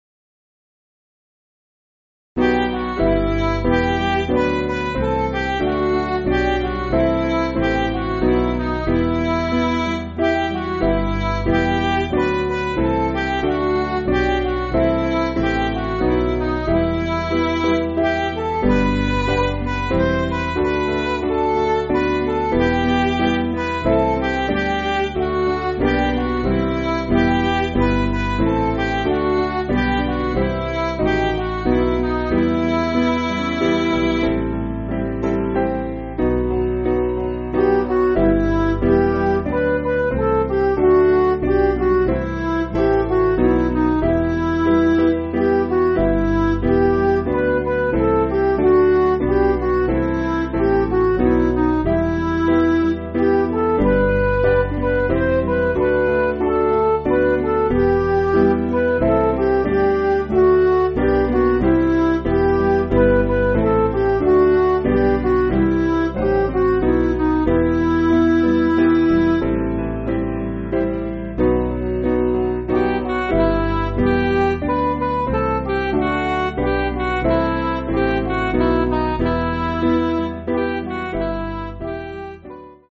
Piano & Instrumental
(CM)   4/Em
Midi